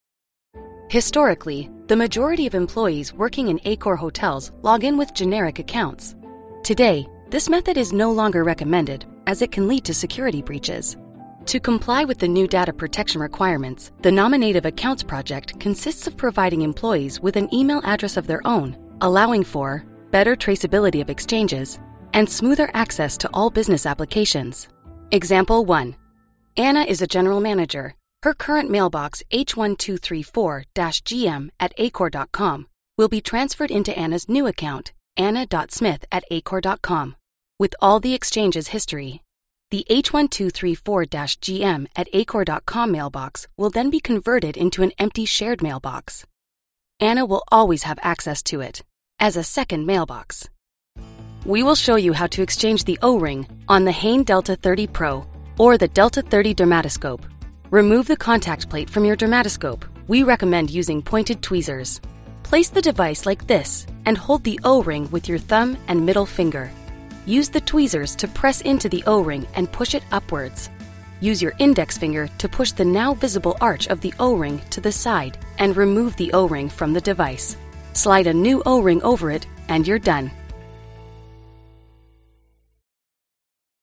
Inglês (canadense)
E-learning
Uma voz autêntica, coloquial e tranquila, sem ser a de um locutor.
Com uma qualidade texturizada e natural, entrego performances que são acessíveis, inteligentes e confiáveis.